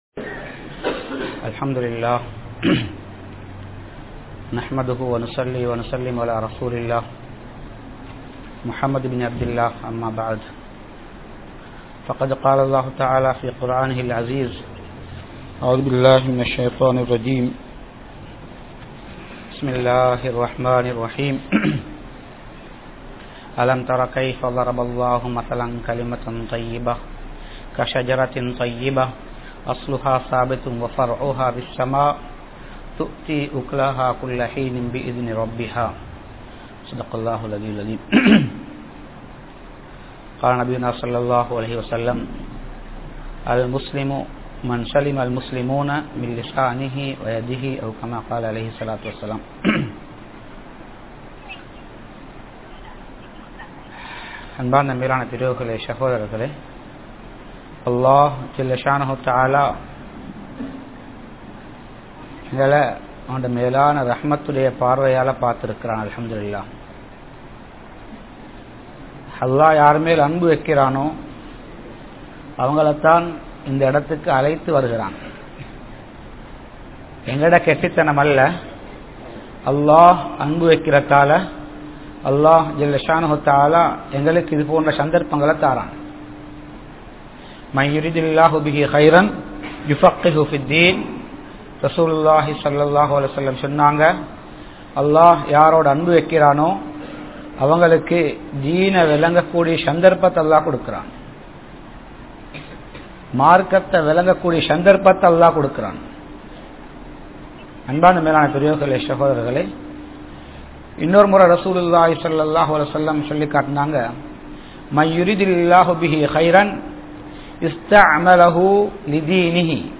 Who Is Dhaee? (இஸ்லாமிய அழைப்பாளன் என்றால் யார்?) | Audio Bayans | All Ceylon Muslim Youth Community | Addalaichenai